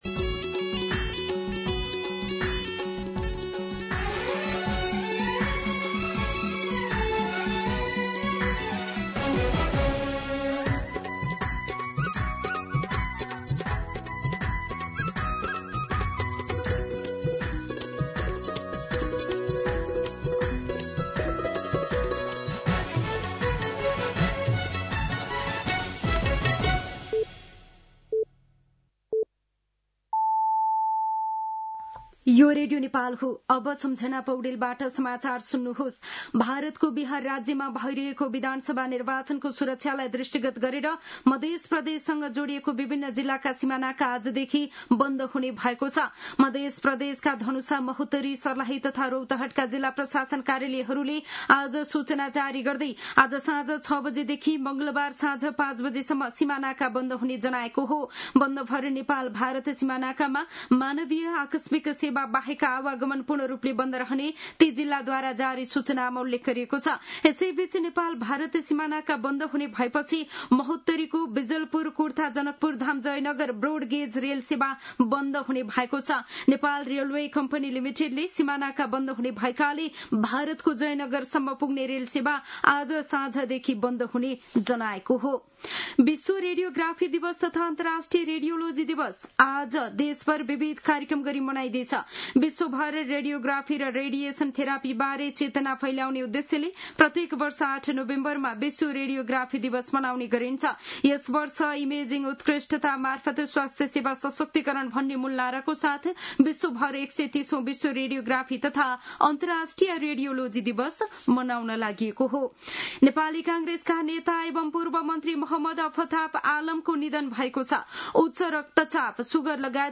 दिउँसो ४ बजेको नेपाली समाचार : २२ कार्तिक , २०८२
4-pm-Nepali-News-.mp3